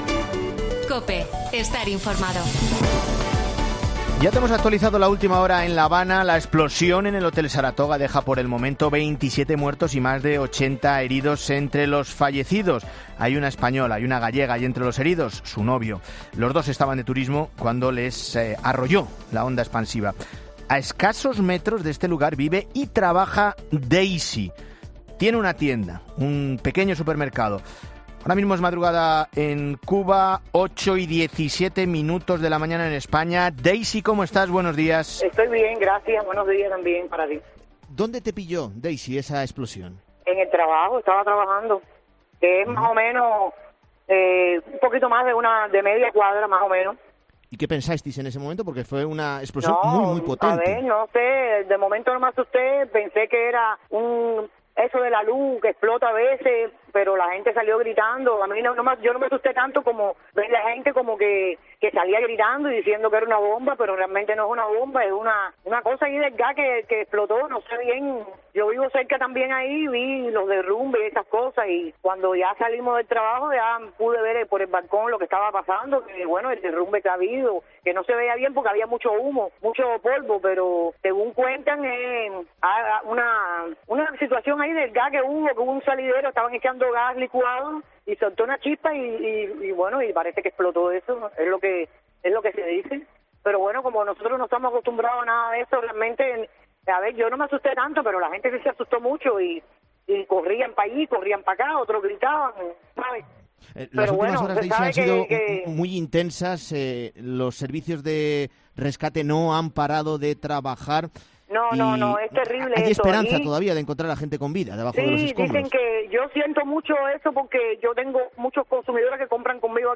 La cubana explica en 'La Mañana de Fin de Semana COPE' cómo vivió la explosión del céntrico Hotel Saratoga